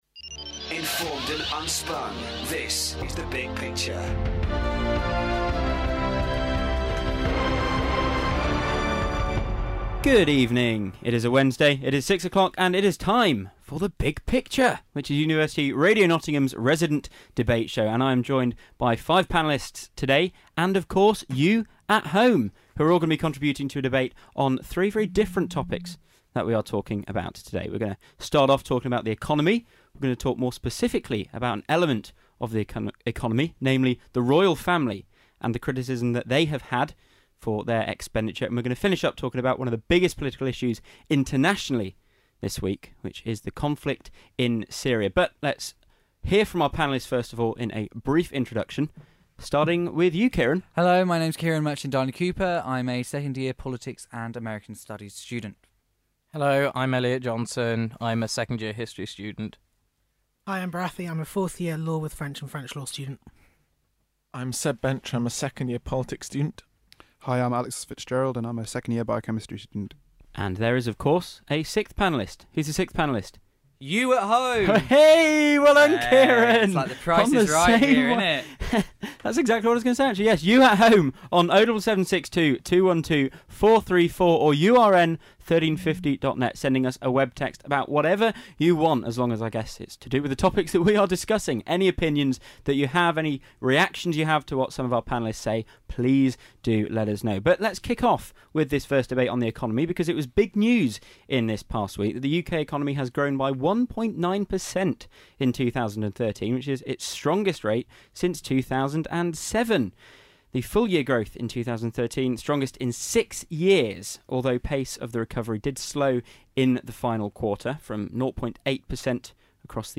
The panel talks about the latest figures on the economic recovery and what they mean for Britain, criticism of the Royal Family over its finances, and the conditions in the Syrian conflict. 51:08 minutes (46.82 MB) big picture economy royal family Syria Yes, include in podcast listings 6 comments Download audio file